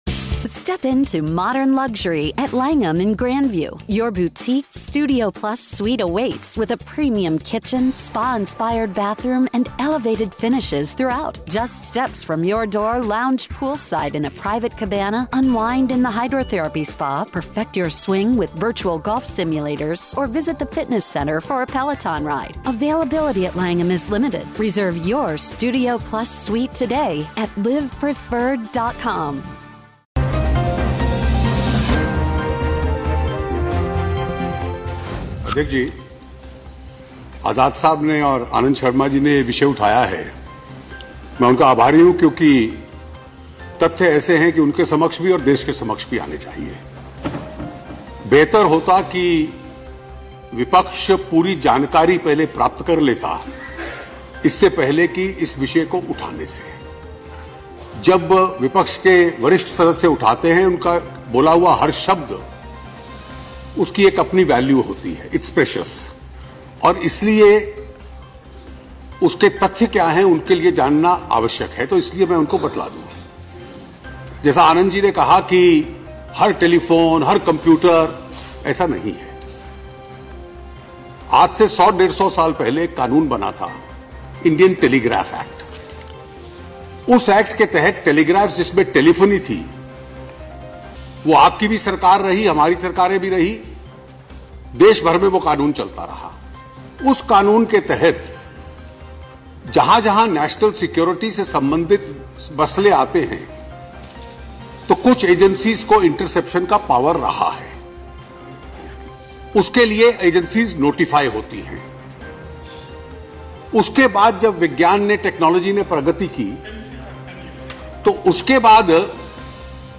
न्यूज़ रिपोर्ट - News Report Hindi / जासूसी मामले पर वित्त मंत्री अरुण जेटली की सफाई